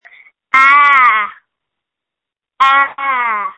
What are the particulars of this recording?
All of these are perfectly good reasons to call and record yourself screaming.